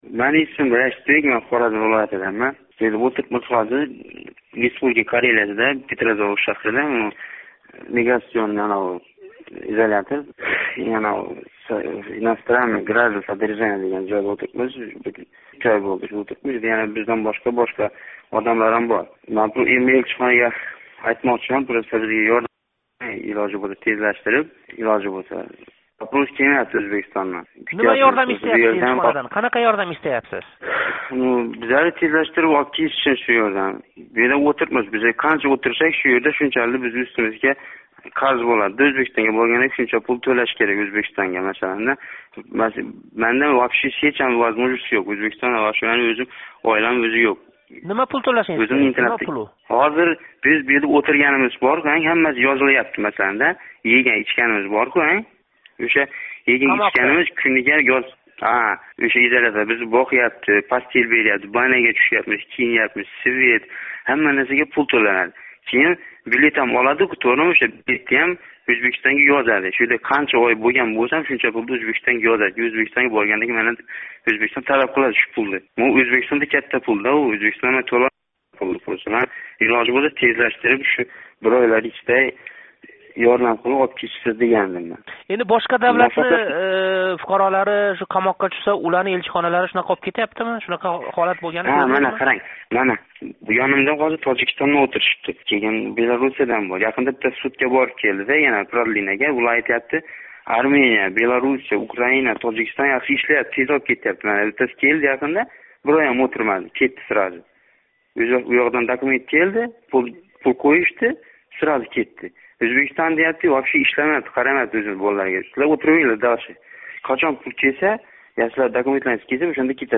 Карелия изоляторида ўтирган ўзбек тутқуни